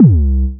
TM88 Boucing808.wav